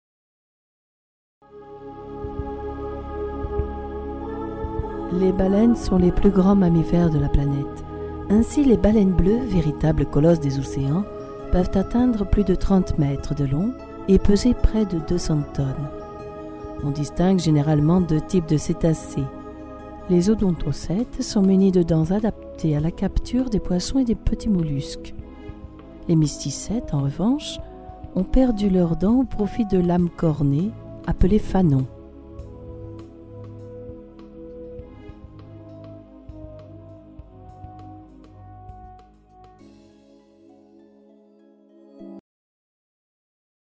Sprechprobe: Sonstiges (Muttersprache):
voiceover stamp medium posed particularly like long texts, documentaries and audio books